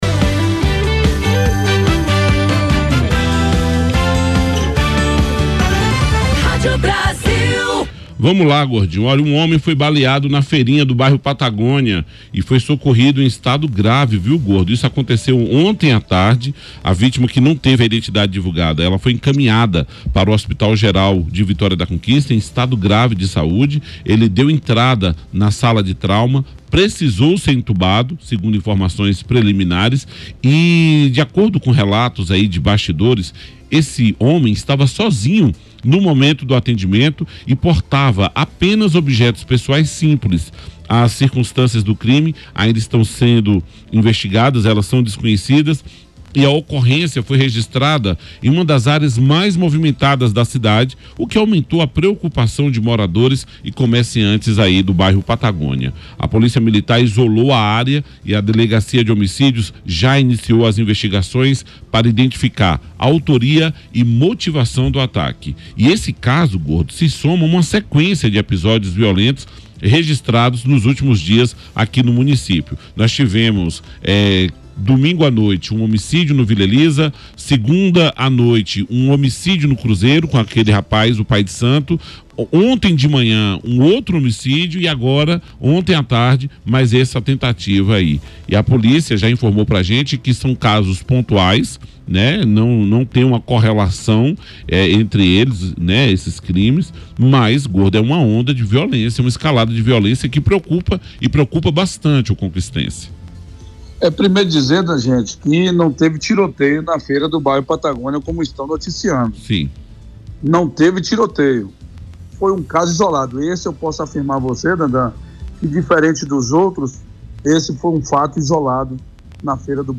A Joia do Sertão Baiano enfrenta uma recente escalada de criminalidade, com o registro de três assassinatos e uma tentativa de homicídio em apenas três dias. O levantamento destaca o clima de insegurança que atinge diferentes pontos, mobilizando as Forças Policiais em uma série de investigações para conter a violência urbana neste mês de abril, conforme acompanha o BLOG DO ANDERSON e reproduz a reportagem da Rádio Brasil.